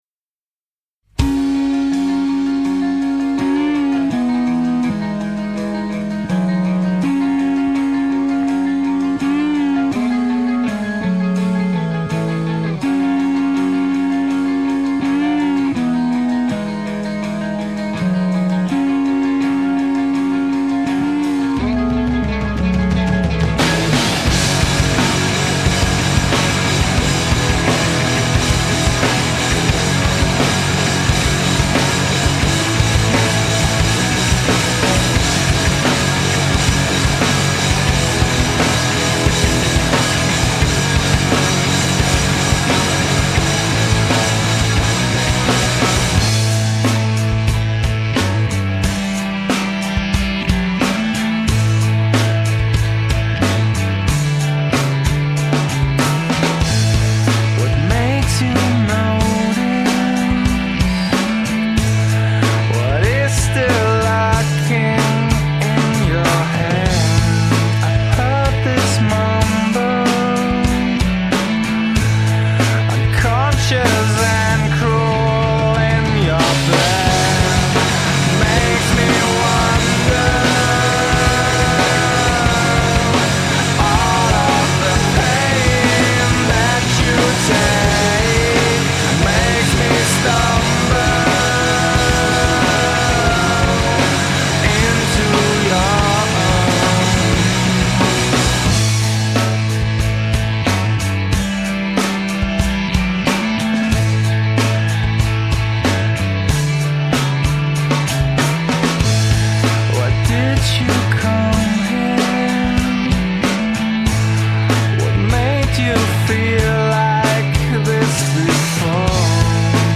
Alternative/Independent